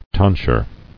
[ton·sure]